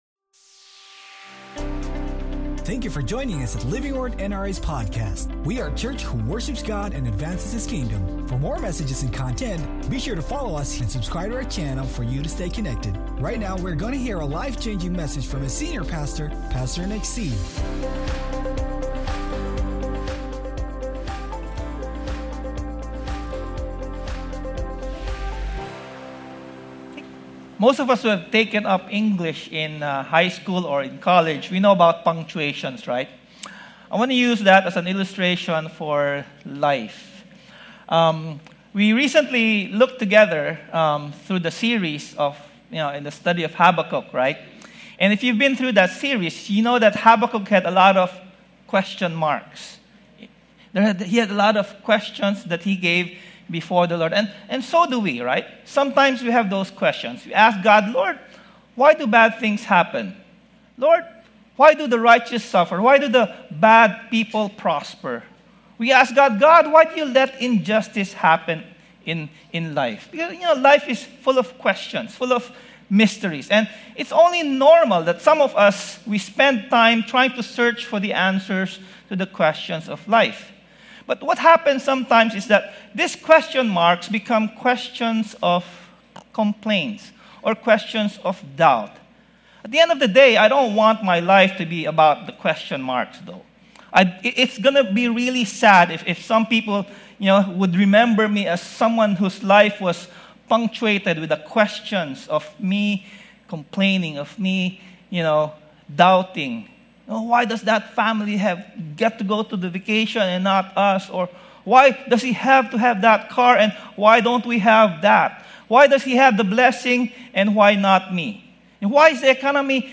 Sermon Title: THE EXCLAMATION POINT OF THANKSGIVING